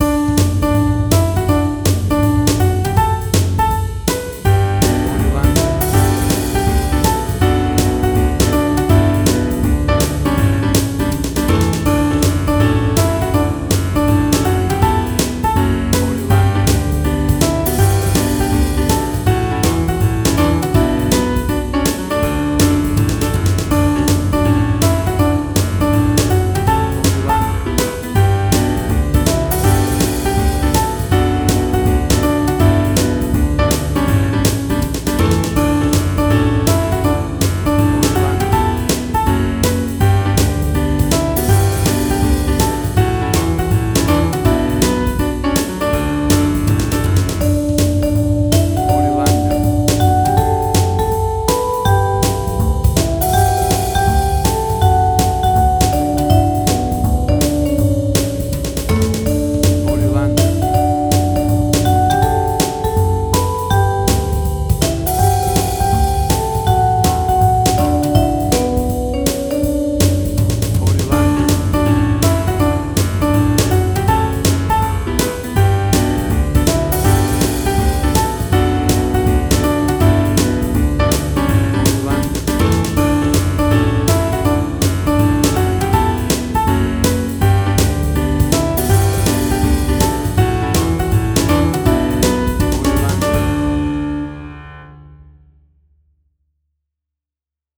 A classic Swing version
WAV Sample Rate: 16-Bit stereo, 44.1 kHz
Tempo (BPM): 161